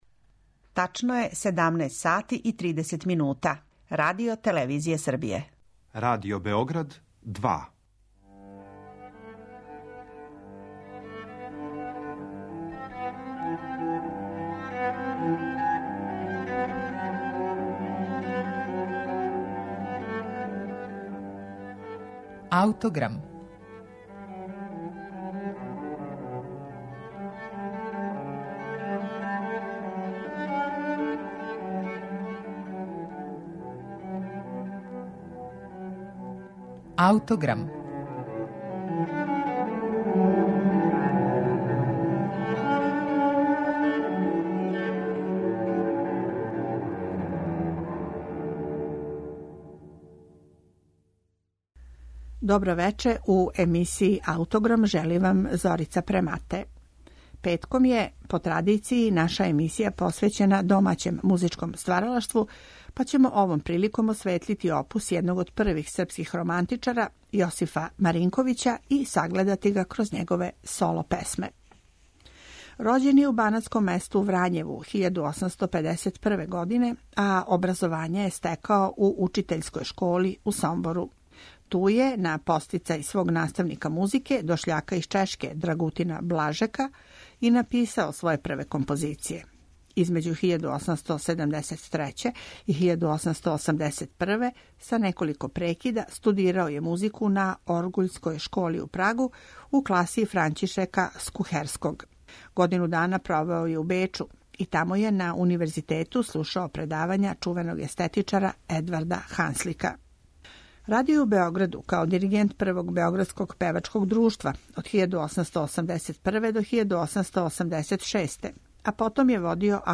соло-песме
бас
мецосопран